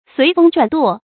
隨風轉舵 注音： ㄙㄨㄟˊ ㄈㄥ ㄓㄨㄢˇ ㄉㄨㄛˋ 讀音讀法： 意思解釋： 比喻順著情勢改變態度。